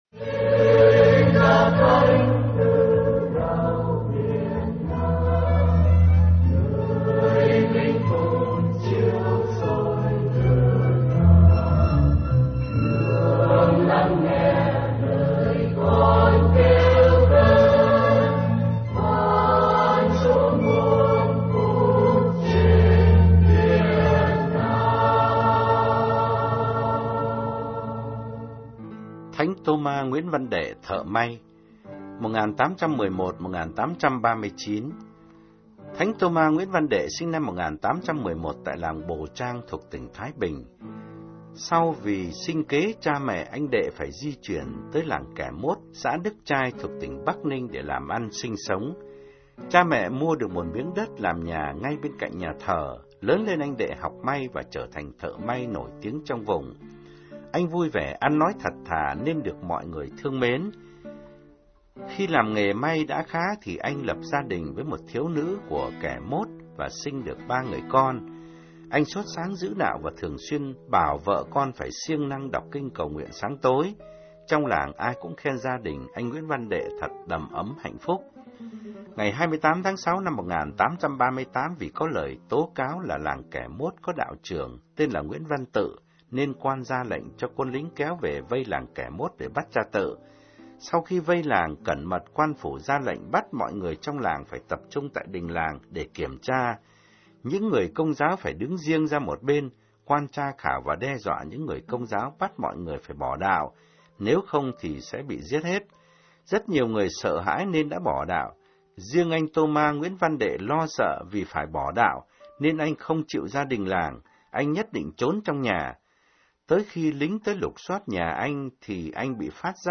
Bạn đang nghe audio Thánh Tôma Nguyễn Văn Đệ - Được thể hiện qua Nguyễn Ngọc Ngạn.